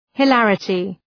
Προφορά
{hı’lærətı}